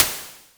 EDIT: Oooh sample the noise, add it to a DVCO sine/tri FM track, and turn the volume of the sample down, then apply velocity mod to it’s volume.
Very expressive toms!
NOISE BURST.wav (57 KBytes)